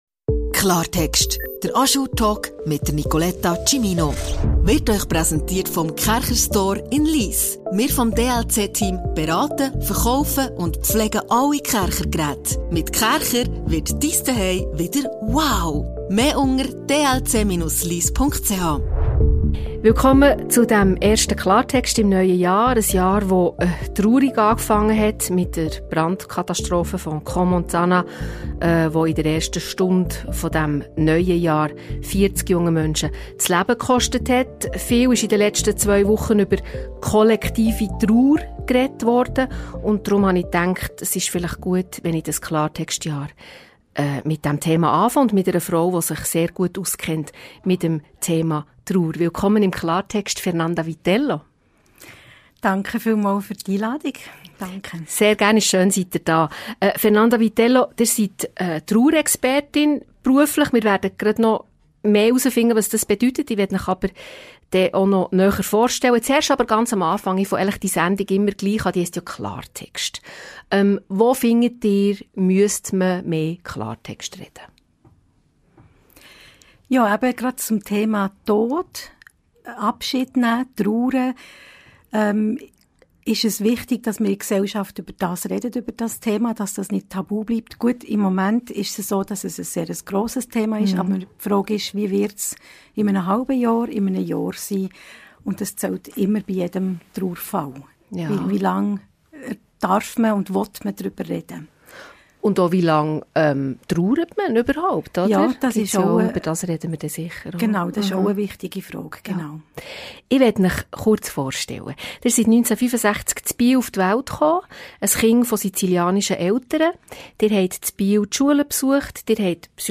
Ein Gespräch über Leben und Tod – und alles dazwischen.